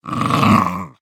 Minecraft Version Minecraft Version snapshot Latest Release | Latest Snapshot snapshot / assets / minecraft / sounds / mob / wolf / puglin / growl1.ogg Compare With Compare With Latest Release | Latest Snapshot
growl1.ogg